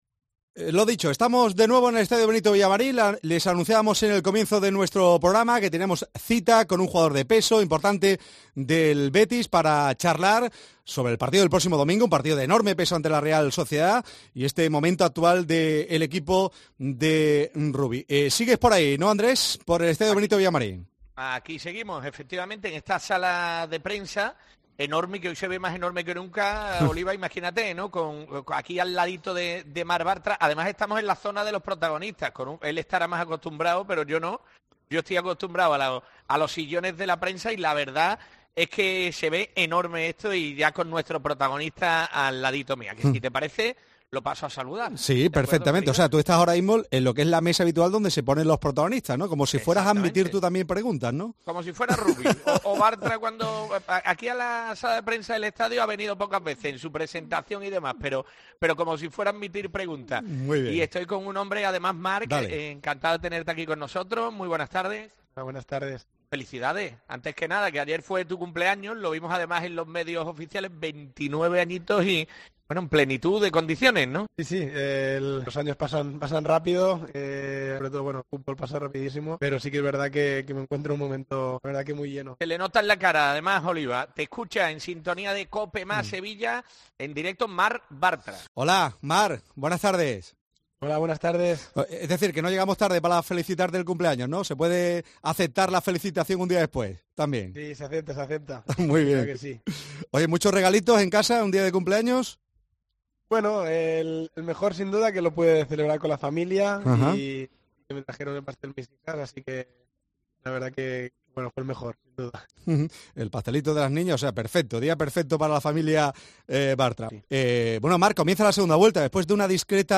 Marc Bartra, en la entrevista concedida a Copa Más Sevilla